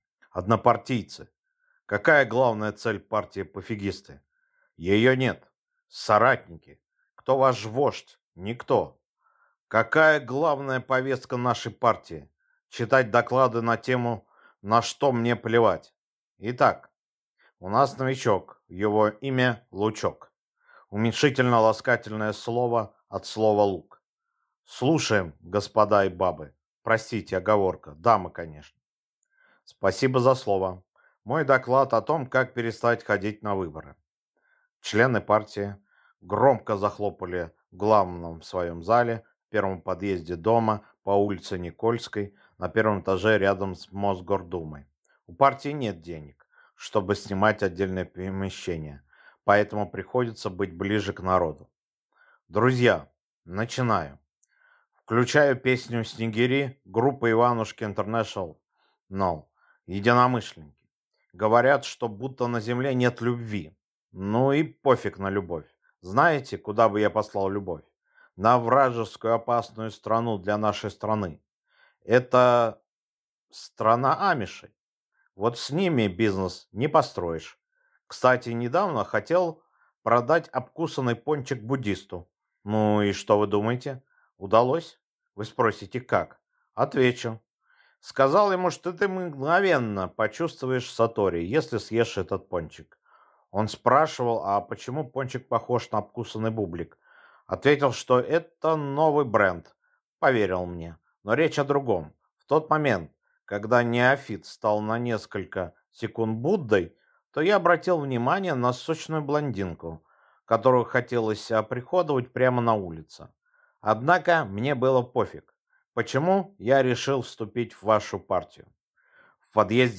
Аудиокнига Партия «Пофигисты» | Библиотека аудиокниг